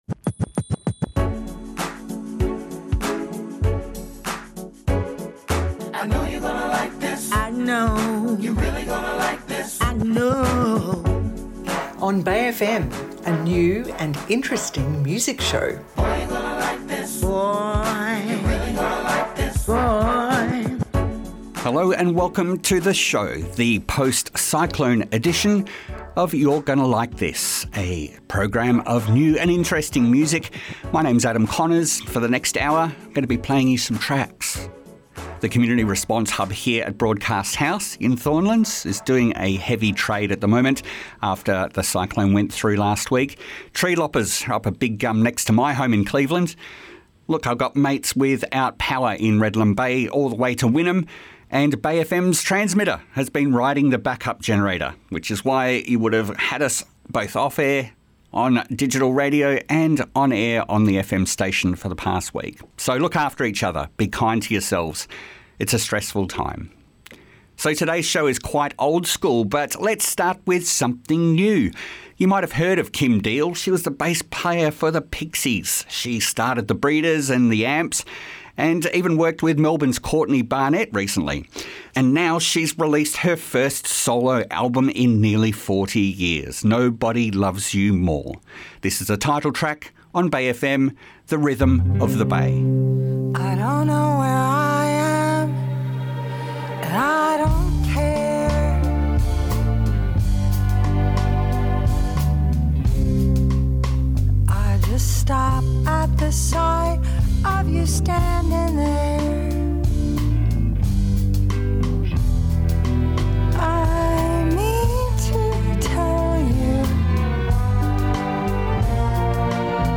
Which is why community radio should exist IMHO.